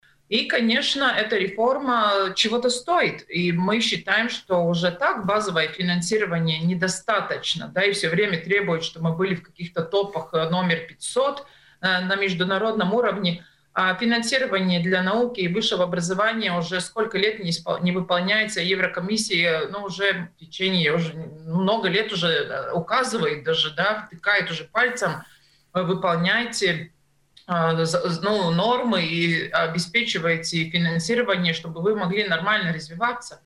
в эфире радио Baltkom